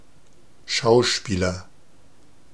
Ääntäminen
Vaihtoehtoiset kirjoitusmuodot (vanhentunut) actour Synonyymit doer plaintiff role actress participant performer player complainant Ääntäminen US UK : IPA : /ˈæk.tə/ US : IPA : /ˈæk.təɹ/ Lyhenteet ja supistumat act.